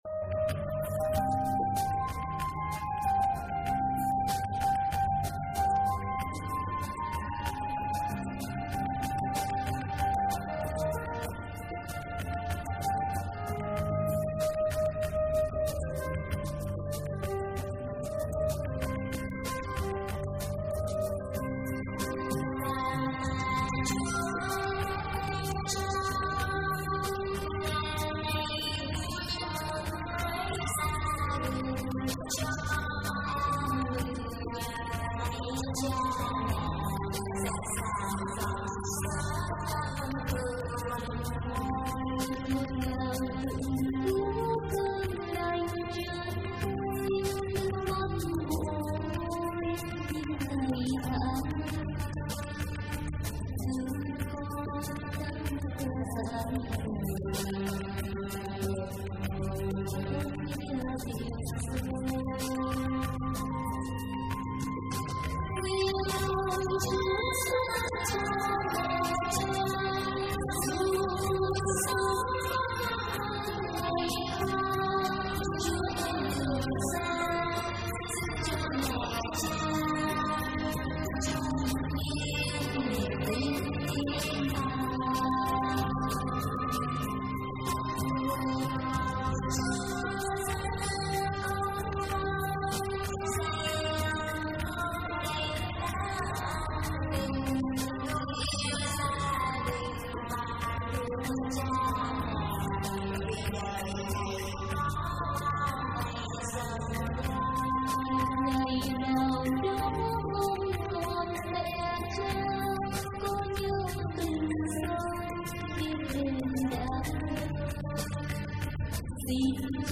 thể hiện hơn 90 bài hát Thánh Ca.